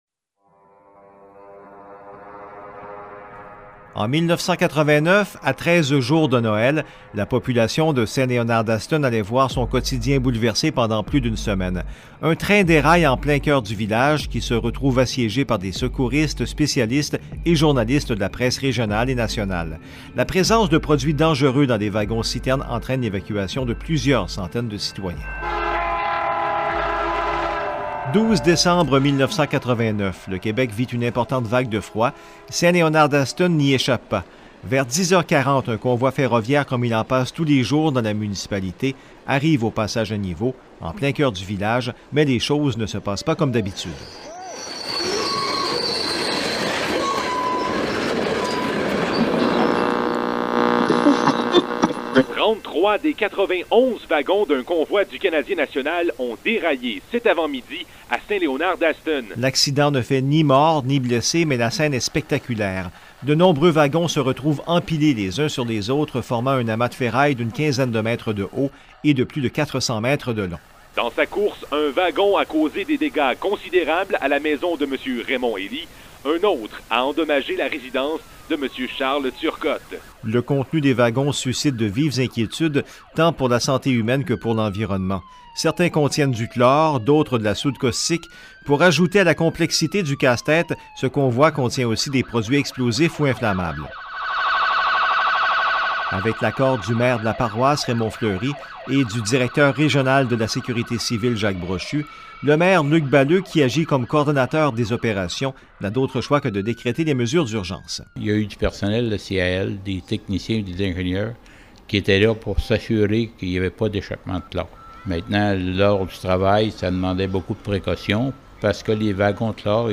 Cette capsule historique fait partie d’un projet initié par la MRC de Nicolet-Yamaska dans le cadre de son Entente de développement culturel avec le ministère de la Culture et des Communications et réalisé de concert avec VIA 90.5 FM.